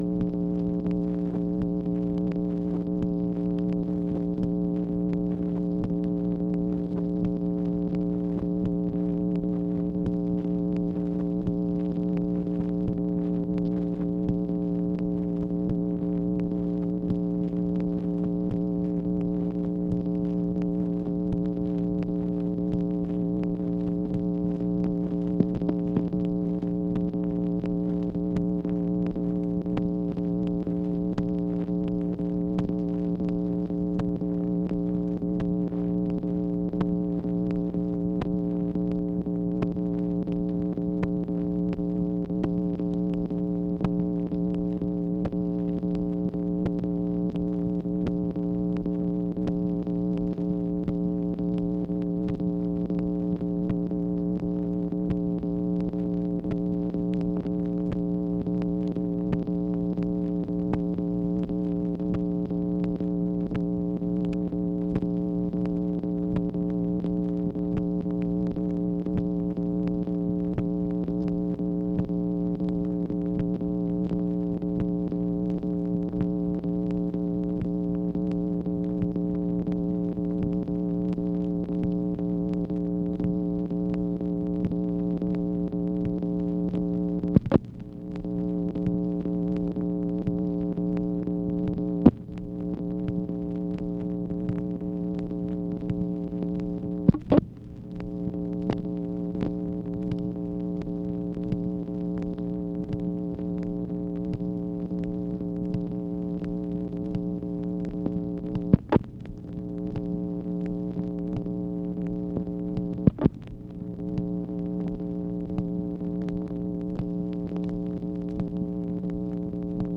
MACHINE NOISE, August 6, 1965
Secret White House Tapes | Lyndon B. Johnson Presidency